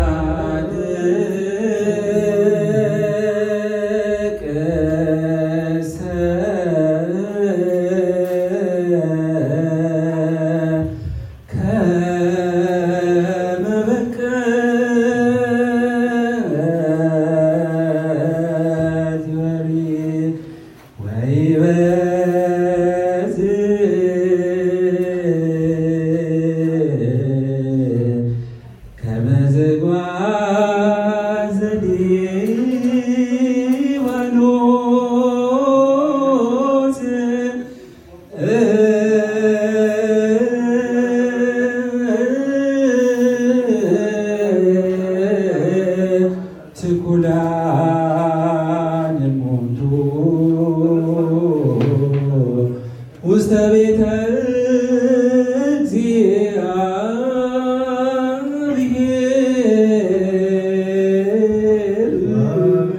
ታህሳስ-፳፬-ተክለ-ሃይማኖት-ምስባክ-ጻድቅሰ-ከመ-በቀልት-ይፈሪ.mp3